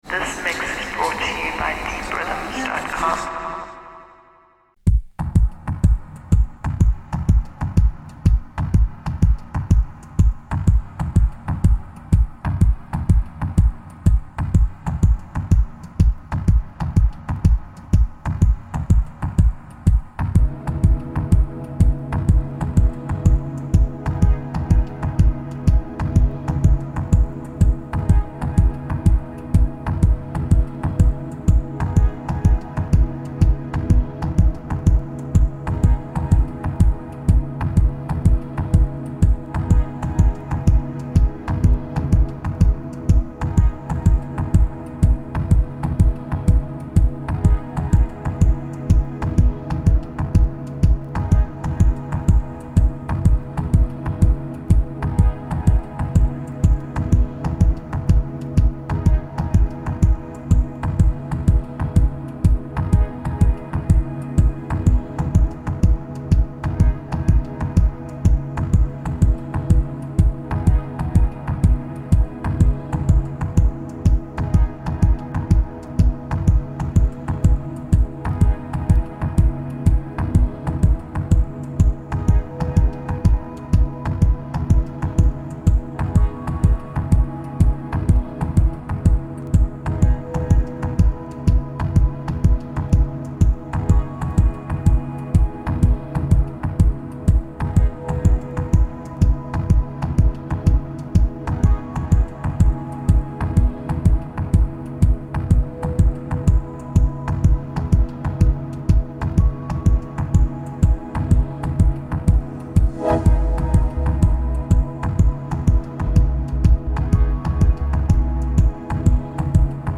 There's some slight clipping going on, hope you don't mind.